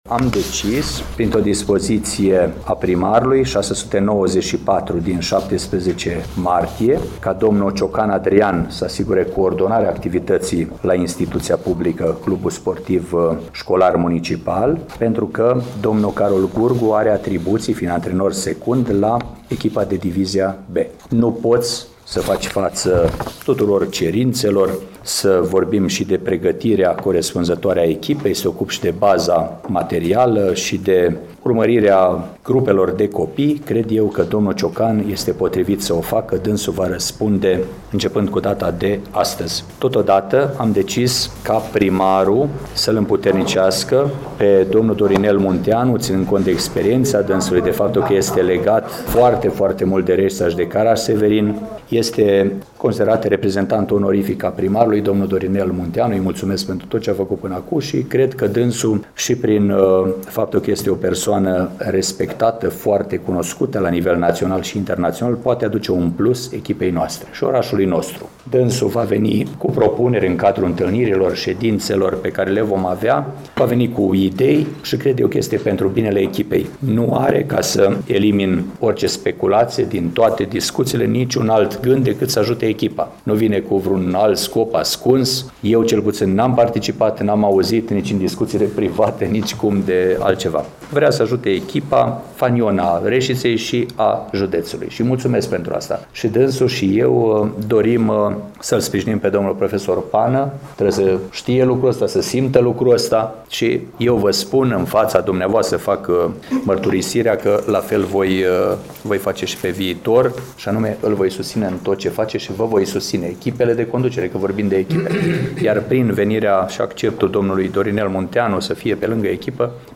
Ascultați declarațiile primarului Mihai Stepanescu: